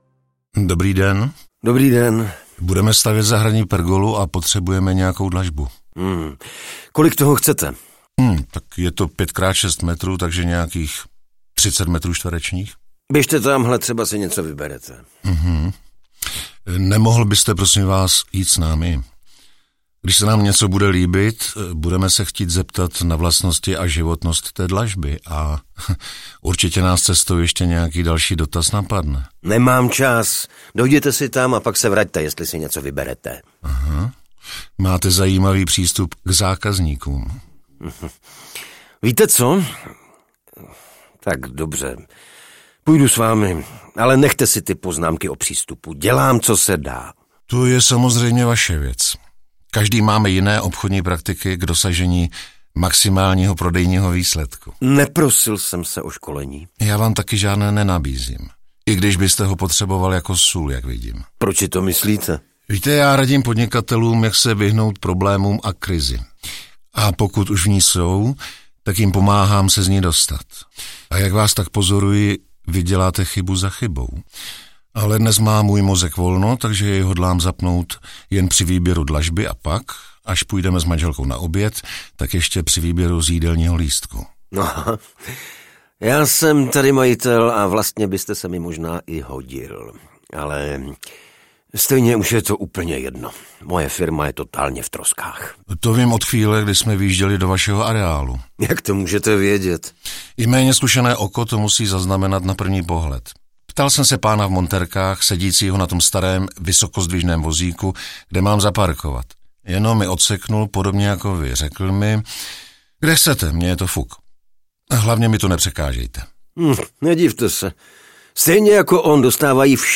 Krotitel rizik podnikání zasahuje: Stavebnictví audiokniha
Ukázka z knihy
Spojení zajímavých příběhů s hlasy známých českých herců podtrhuje atraktivitu celého projektu. Čtvrtý díl se odehrává v prodejně stavebního materiálu. Nevěrná manželka a následný rozvod byl málem příčinou krachu zavedené firmy.
• InterpretAlexej Pyško